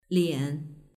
liǎn